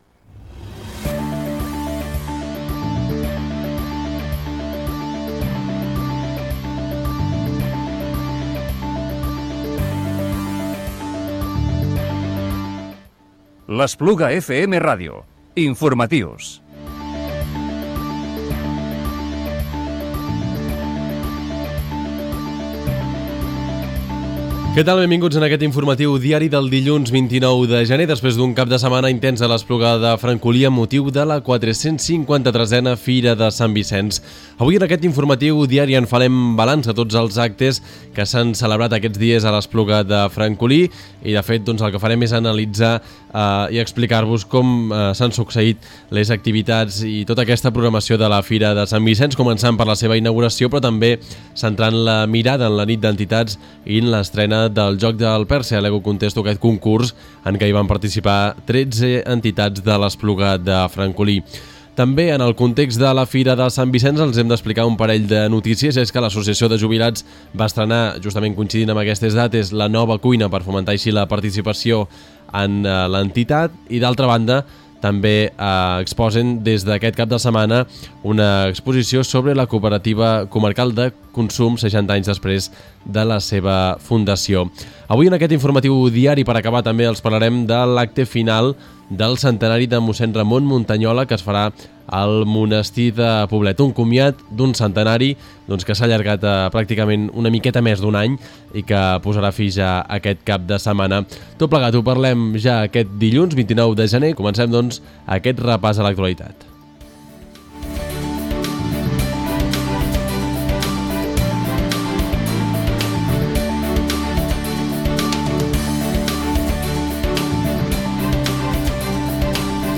Informatiu Diari del dilluns 29 de gener del 2018